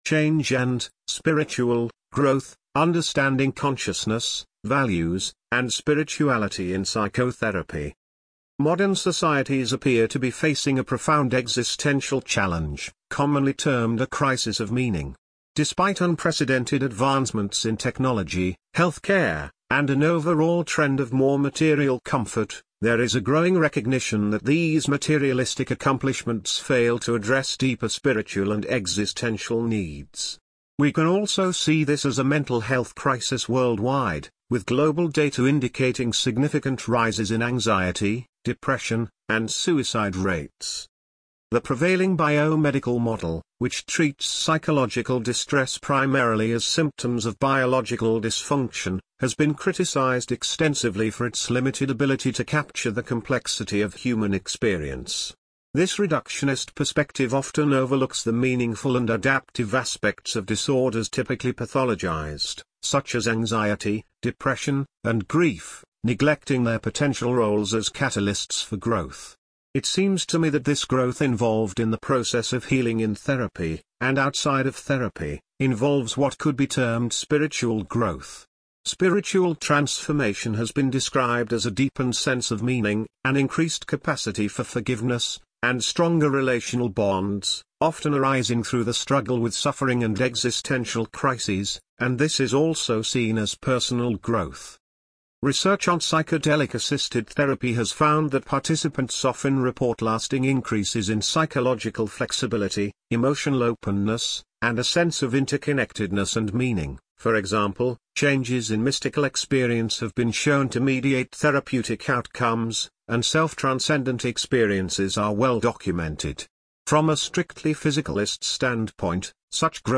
Here is an audio version of this blog.